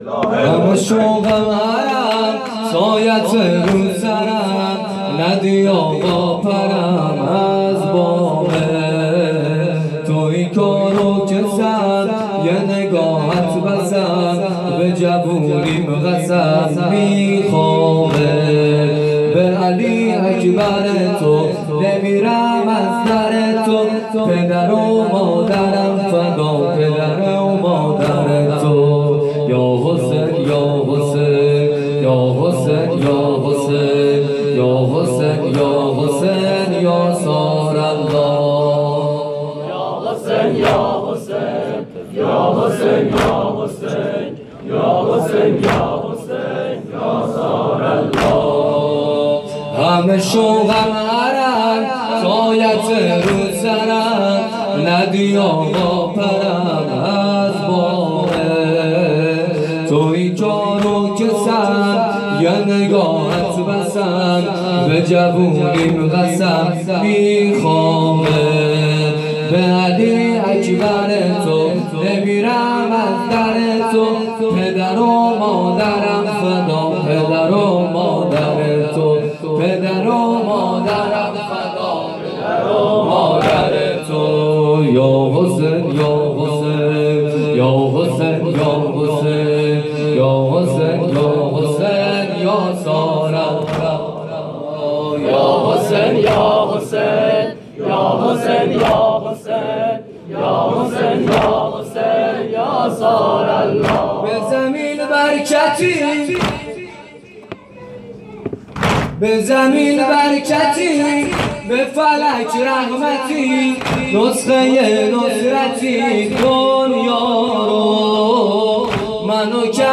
شب اول محرم ۹۷ هیئت صادقیون(ع)